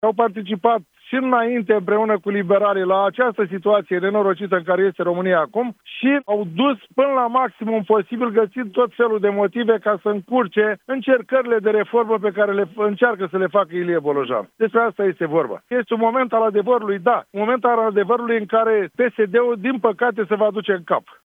Conducerea partidului încearcă să-i manipuleze pe cei 5 mii de membri care vor participa la vot, spune în exclusivitate la Europa FM, primarul PSD din Buzău, Constantin Toma.